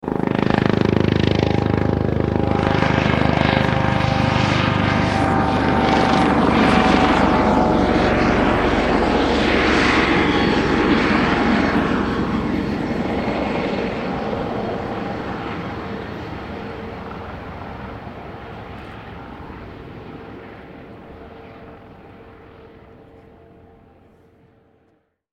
Звук двигателя Бабы-Яги